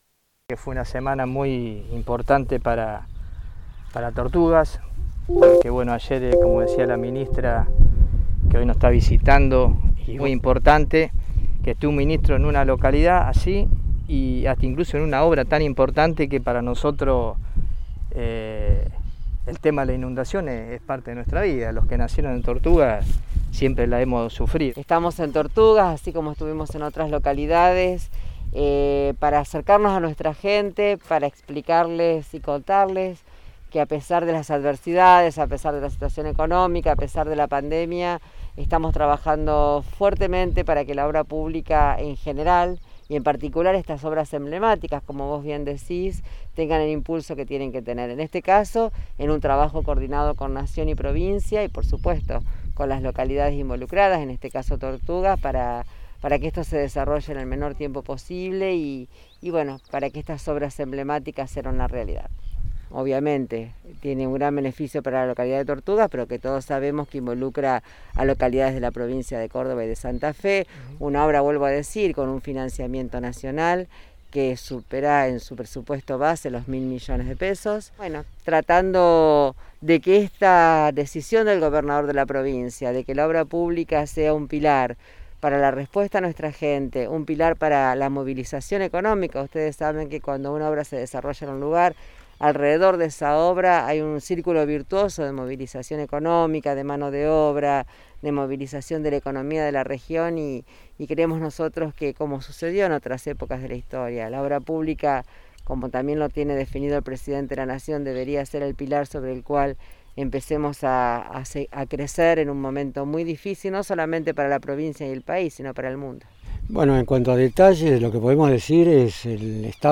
Declaraciones de las autoridades en Tortugas